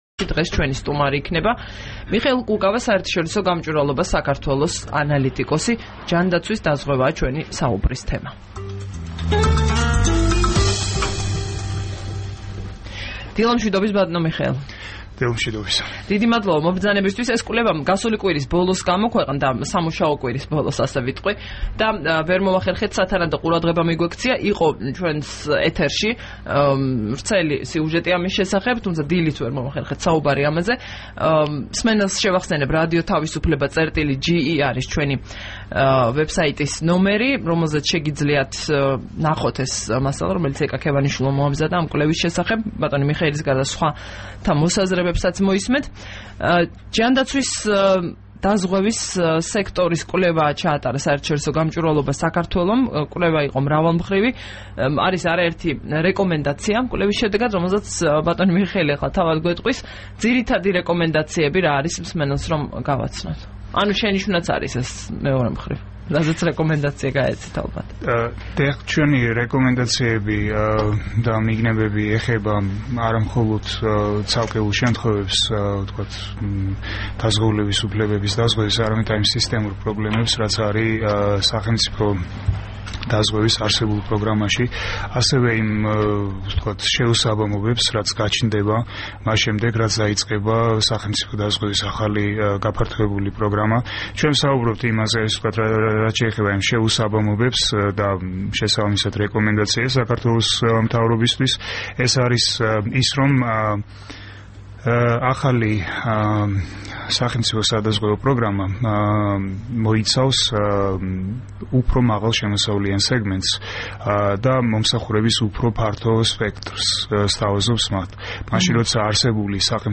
საუბარი მიხეილ კუკავასთან